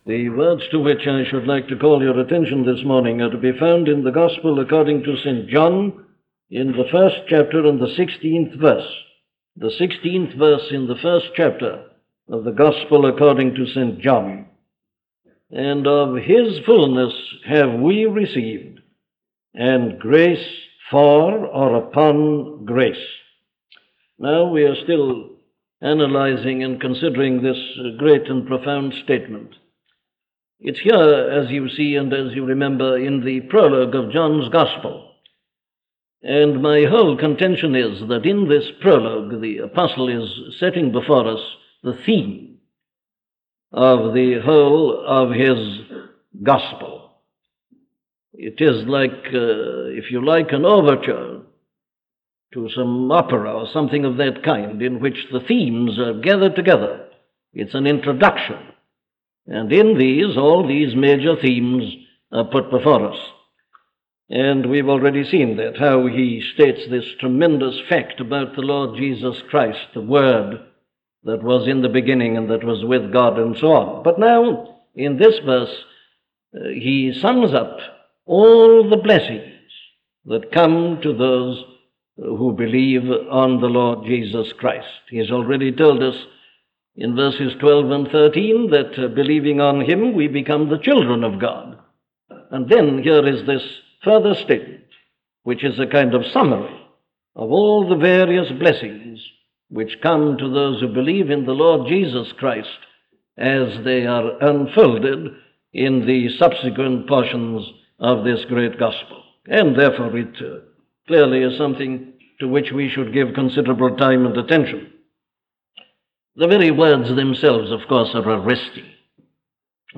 An audio library of the sermons of Dr. Martyn Lloyd-Jones.